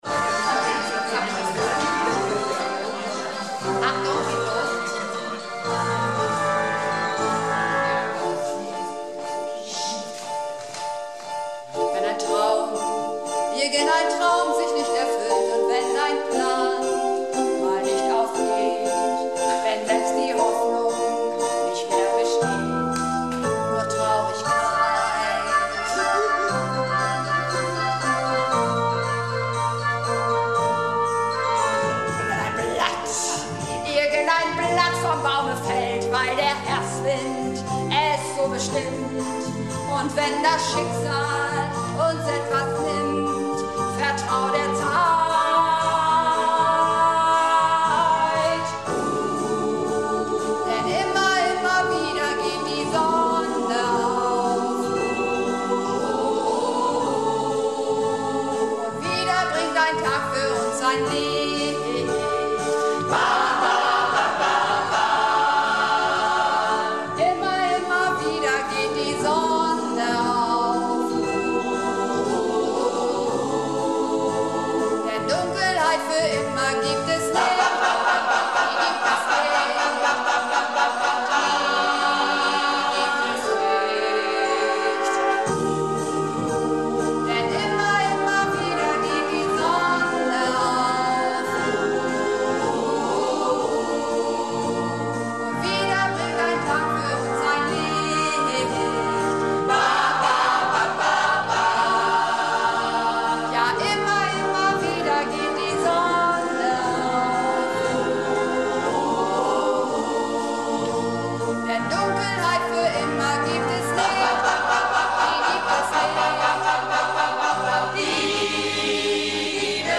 Das ganz gro�e Los - Chorprobe am 19.08.14